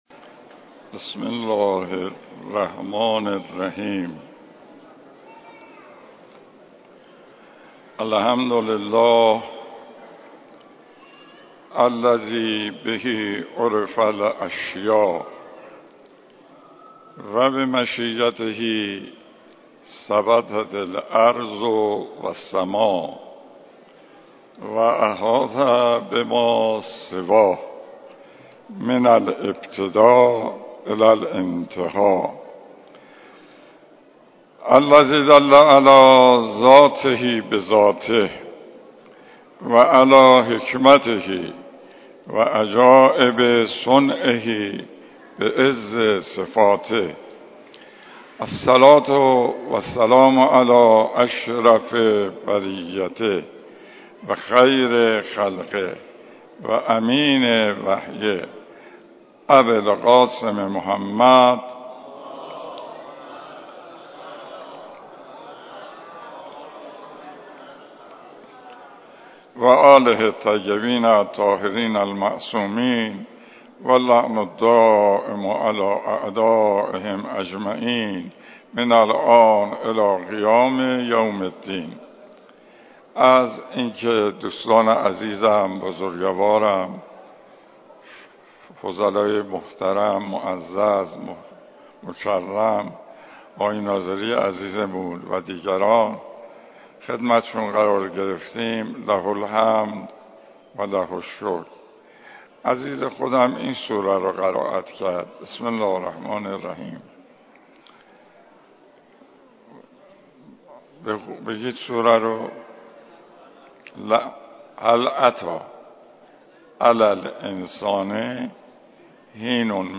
جلسات درس اخلاق